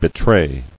[be·tray]